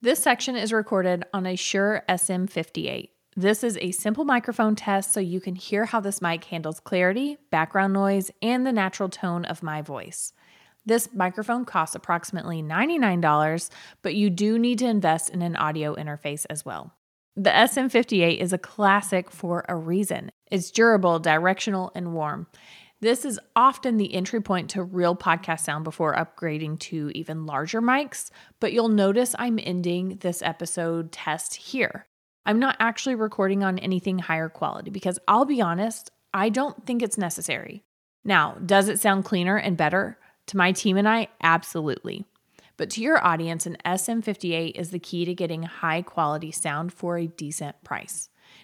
Shure SM58-LC Cardioid Dynamic Vocal Microphone
Listen to the Shure SM58
Shure-SM58.mp3